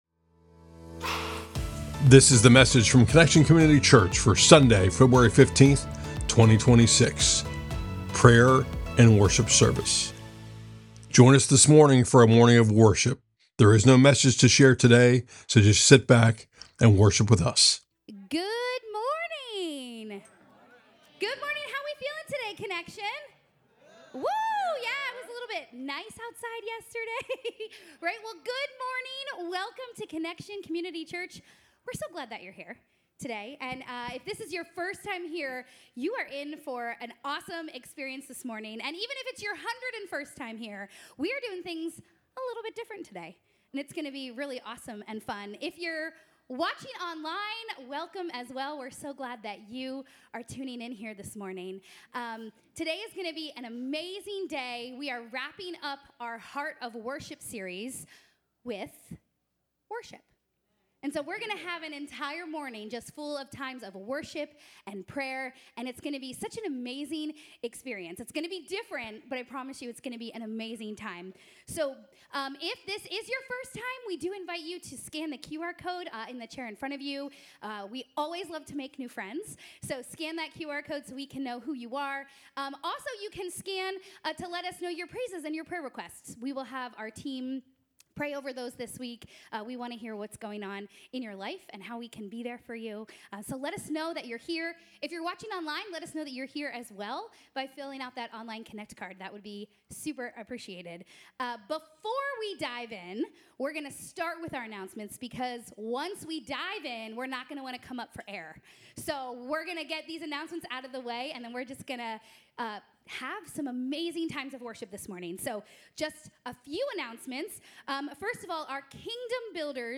Prayer & Worship Service - Pocket CCC